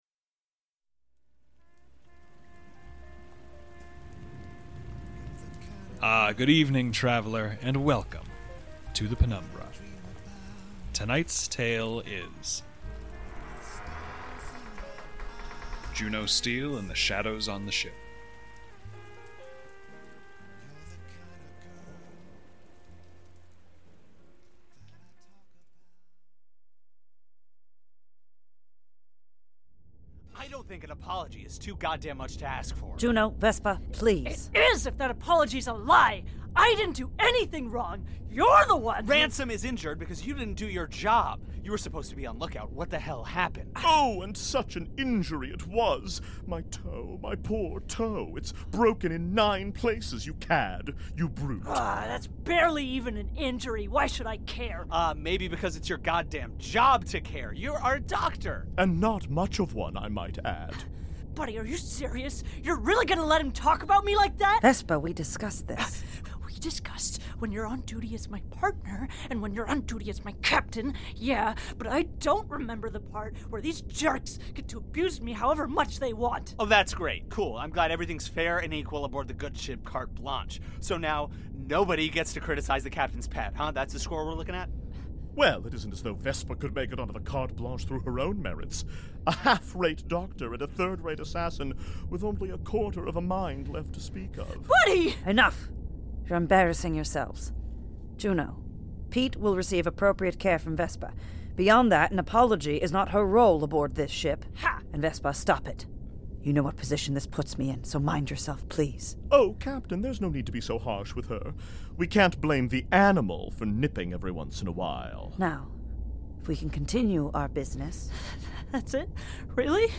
Fiction, Thirst, Second Citadel, Juno Steel, Scifi, Horror, Audio Drama, Rusty Quill, Audio Fiction, The Penumbra Podcast, Penumbra, Thirst Podcast, Performing Arts, Arts, Comedy, Science Fiction